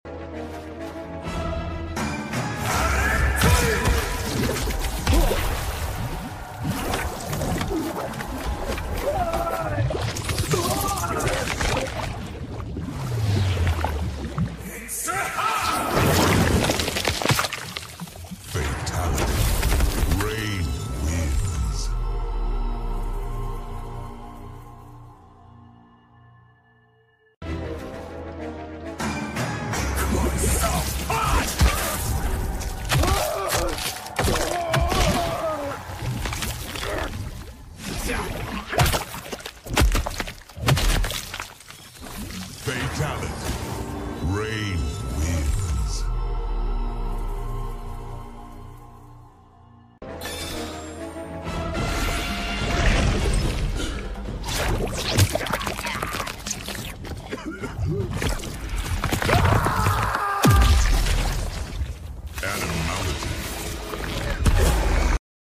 Fatality Rain Mortal Kombat MK Sound Effects Free Download